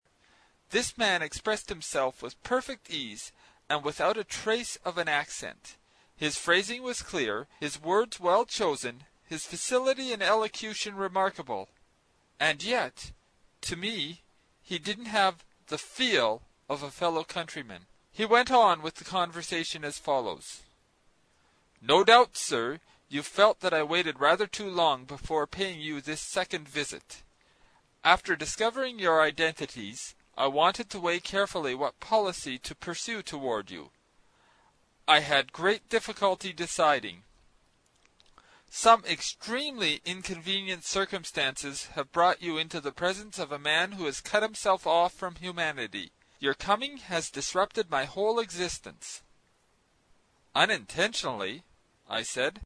英语听书《海底两万里》第132期 第10章 水中人(3) 听力文件下载—在线英语听力室
在线英语听力室英语听书《海底两万里》第132期 第10章 水中人(3)的听力文件下载,《海底两万里》中英双语有声读物附MP3下载